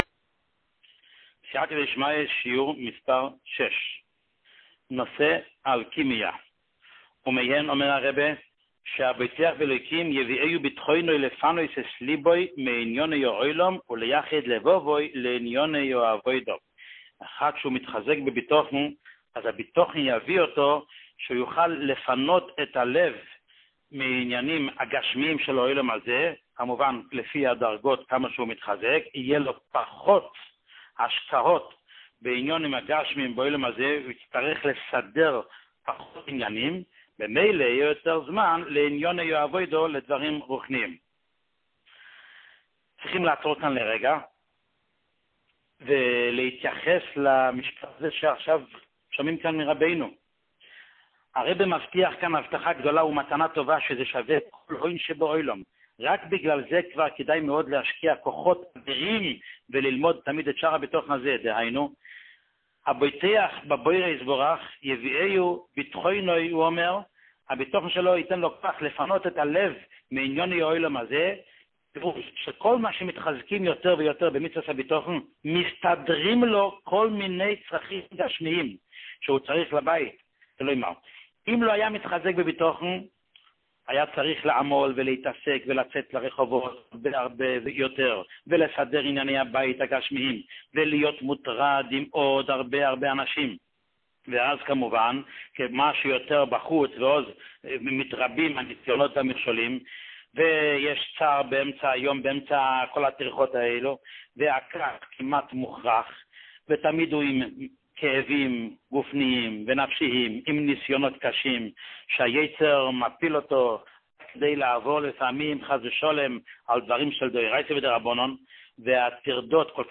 שיעור מספר 6